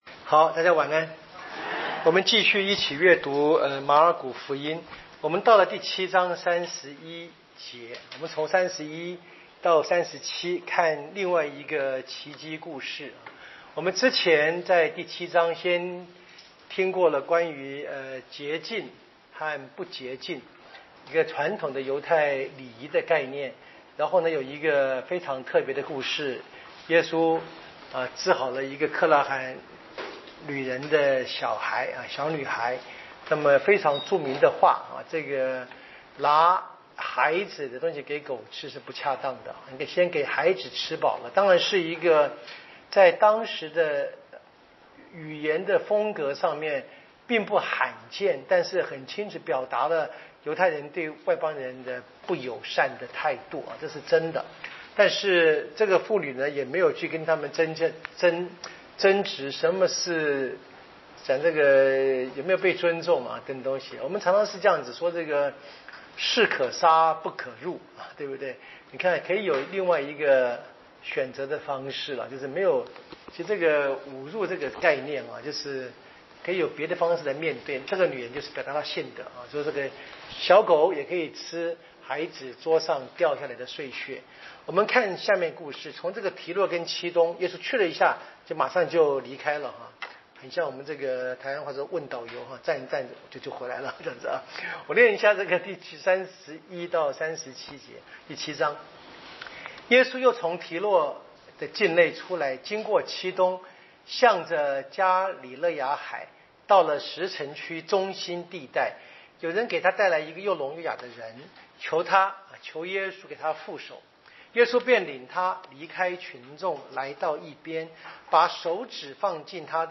【圣经讲座】《马尔谷福音》